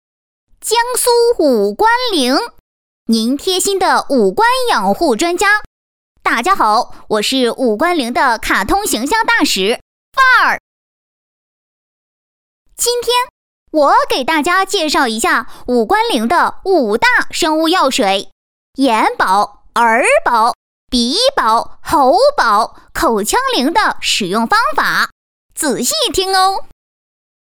【男童】阳关可爱